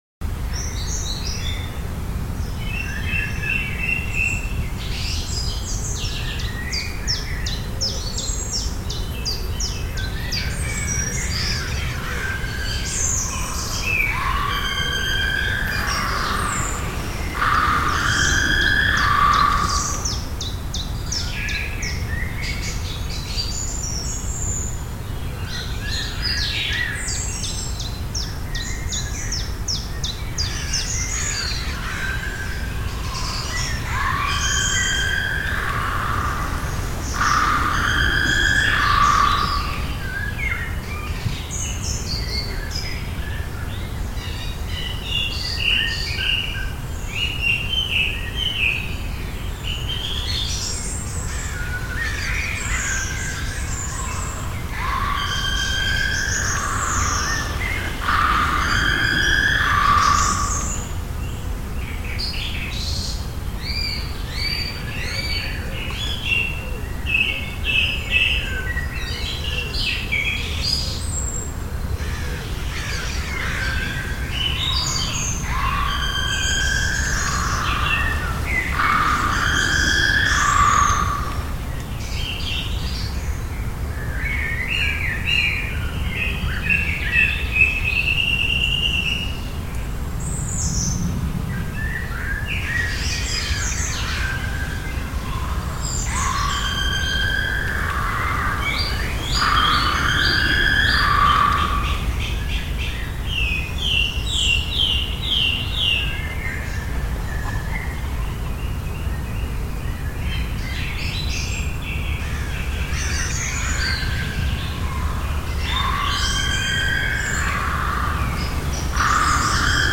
forest.mp3